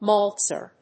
/mˈɔːltstɚ(米国英語)/